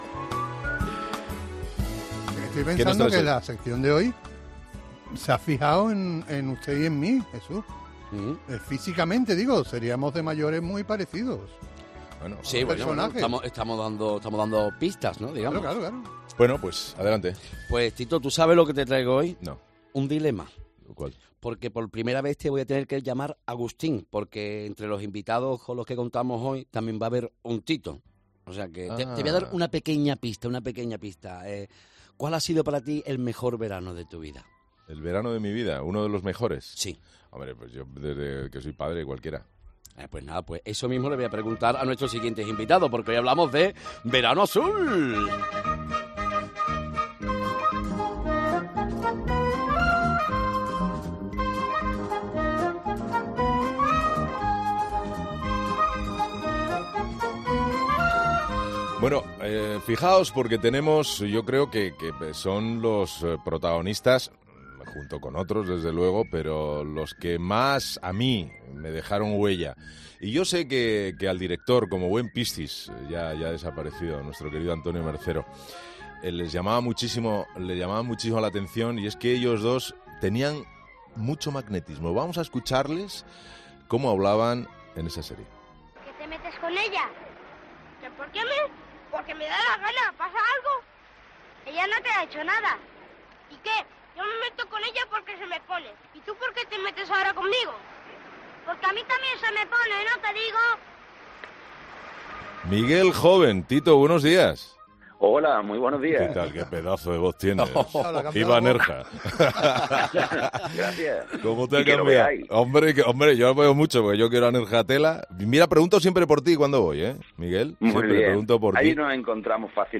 Y este verano no podía ser diferente por lo que en ‘Herrera en COPE’ hemos hablado con dos de sus protagonistas: Tito y Piraña; o lo que es lo mismo: Miguel Joven y Miguel Ángel Valero.